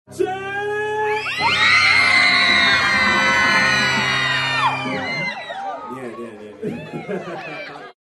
Звуки фанатов на концерте
Здесь вы найдете громкие крики поддержки, ритмичные аплодисменты и эмоциональные реакции зала.
Звук старта концерта и визг фанаток (кричащая толпа девушек)